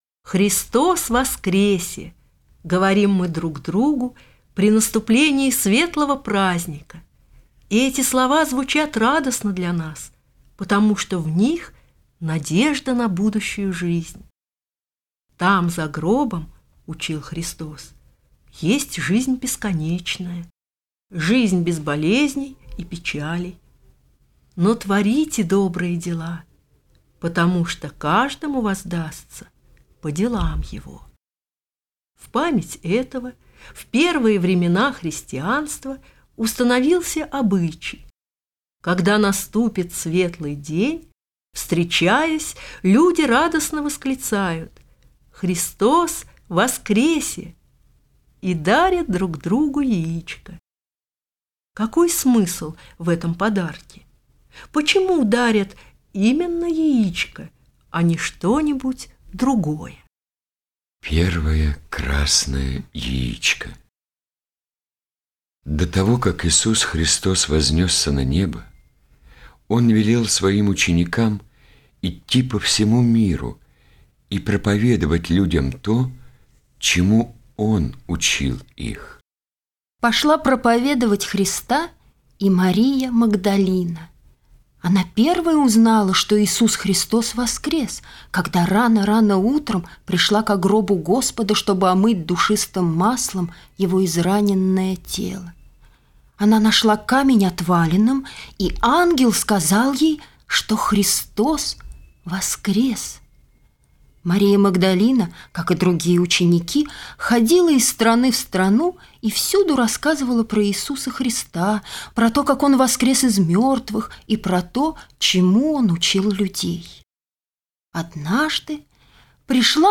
Первое красное яичко - аудио рассказ - слушать онлайн